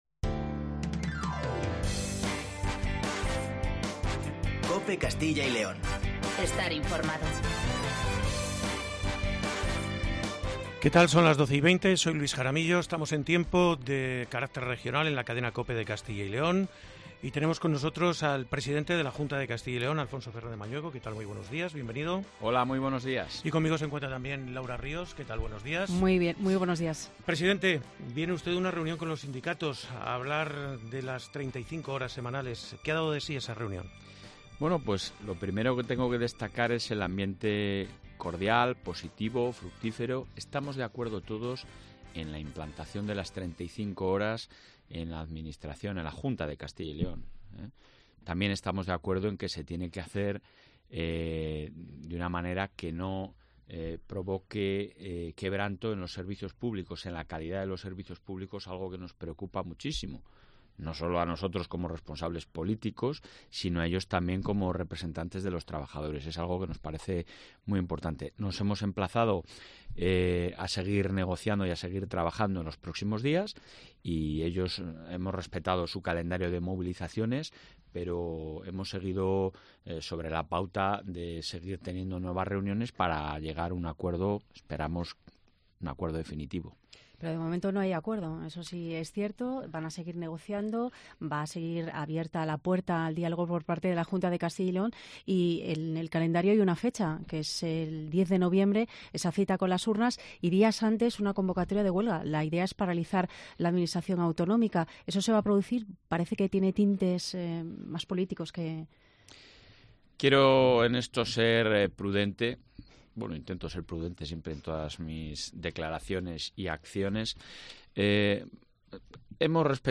AUDIO: Entrevista al presidente de la Junta de Castilla y León Alfonso Fernandez Mañueco.